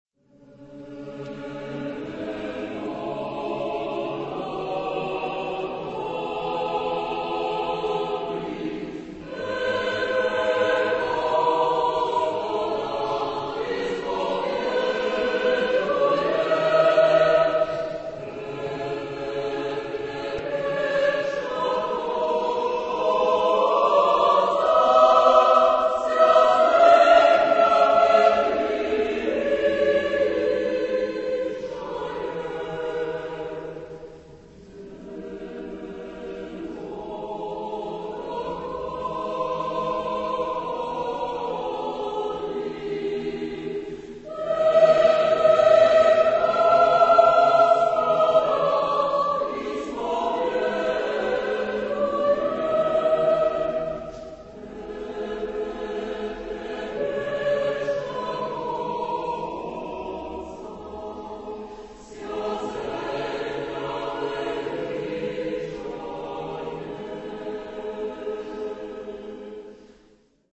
Genre-Style-Forme : Choral ; Sacré
Caractère de la pièce : majestueux
Type de choeur : SSATTBB  (7 voix mixtes )
Consultable sous : 20ème Sacré Acappella